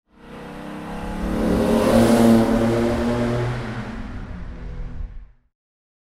Mercedes-Benz 300 SL (1960) - Vorbeifahrt
Mercedes_300_SL_Vorbeifahrt.mp3